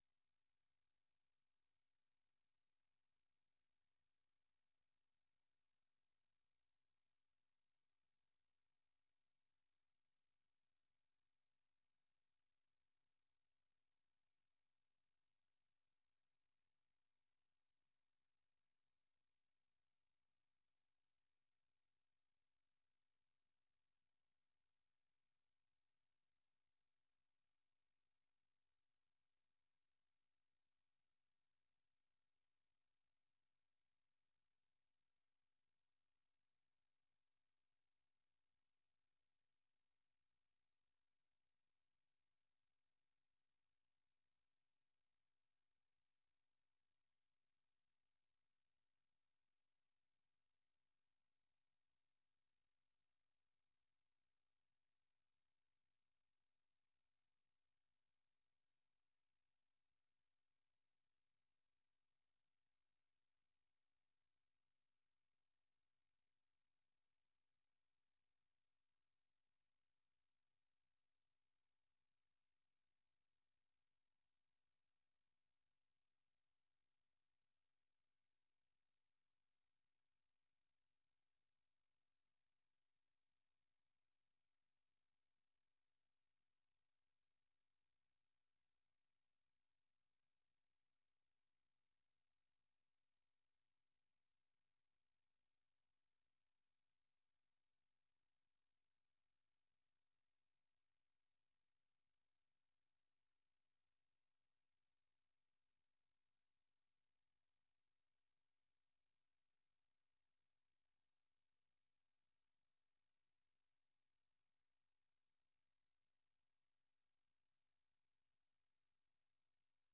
The URL has been copied to your clipboard 分享到臉書 分享到推特網 No media source currently available 0:00 0:30:00 0:00 下載 128 kbps | MP3 64 kbps | MP3 時事經緯 時事經緯 分享 時事經緯 分享到 美國之音《時事經緯》每日以30分鐘的時間報導中港台與世界各地的重要新聞，內容包括十分鐘簡短國際新聞，之後播出從來自世界各地的美國之音記者每日發來的採訪或分析報導，無論發生的大事與你的距離是遠還是近，都可以令你掌握與跟貼每日世界各地發生的大事！